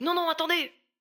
VO_ALL_Interjection_01.ogg